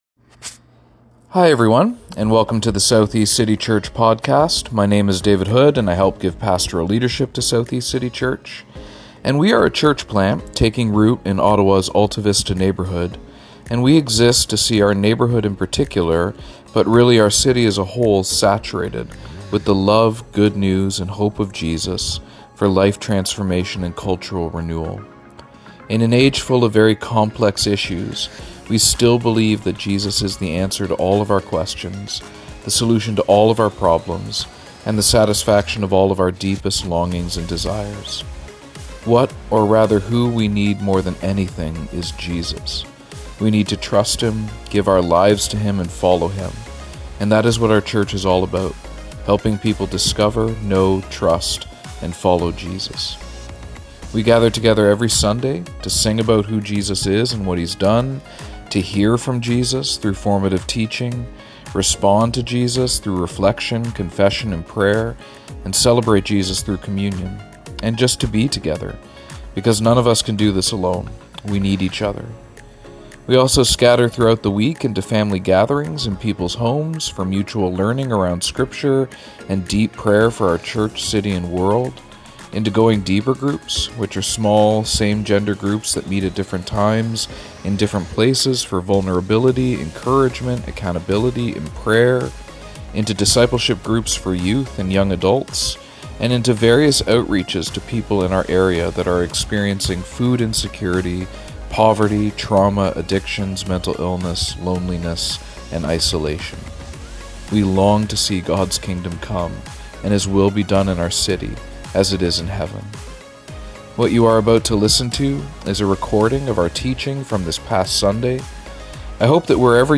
Part 1 Preacher